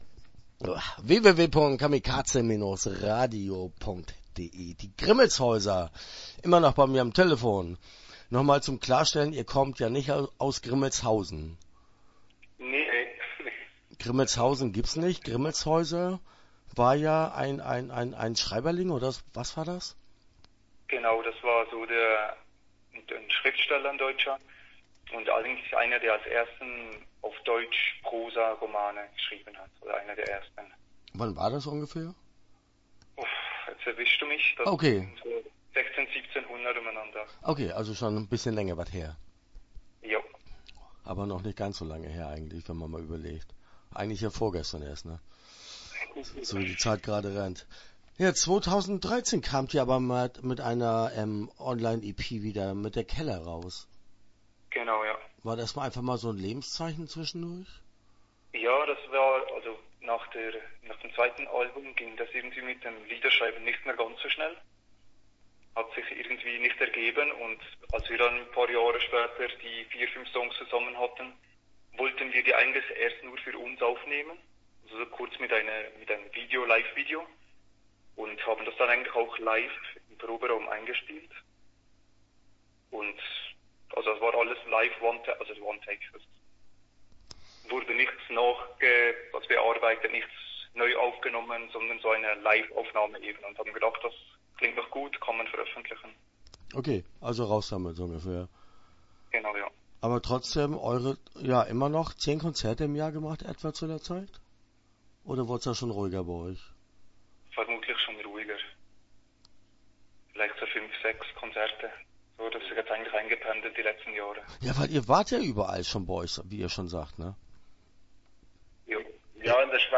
Die Grimmelshäuser - Interview Teil 1 (9:43)